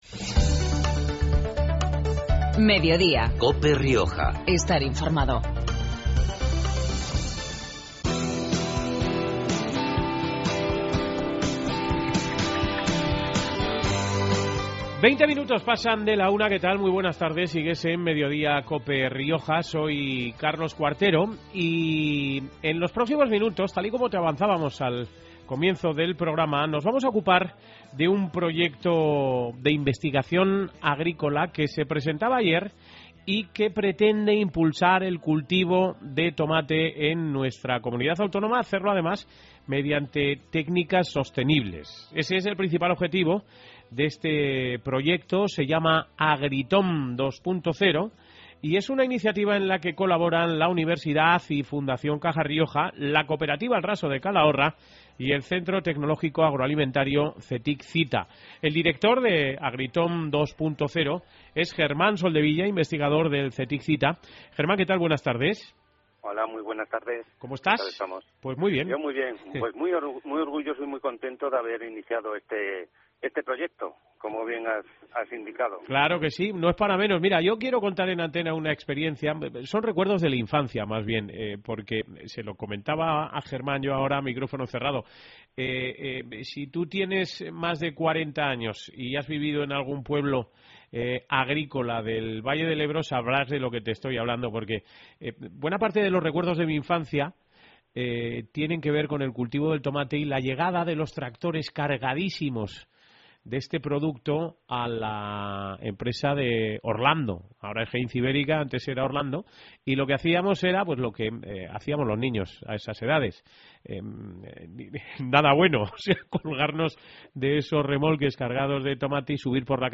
Mediodía en Cope Rioja Baja (martes, 21 mayo. 13:20-13:30 horas).